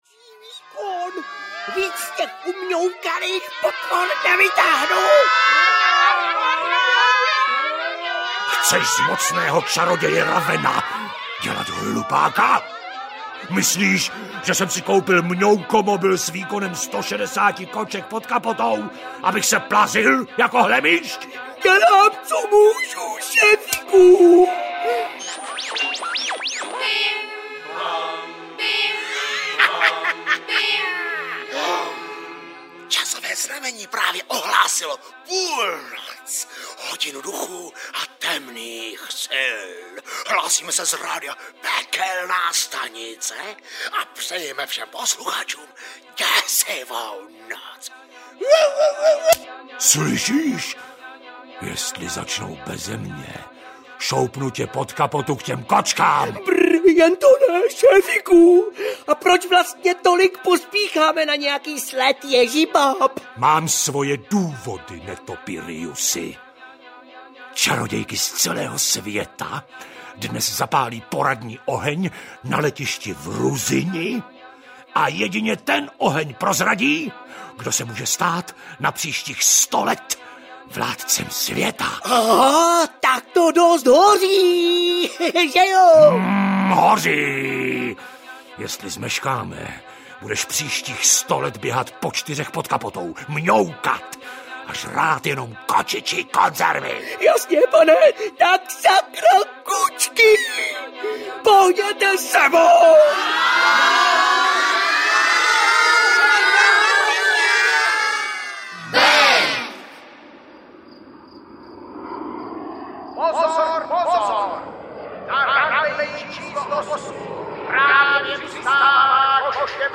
Cirkus Svět audiokniha
Ukázka z knihy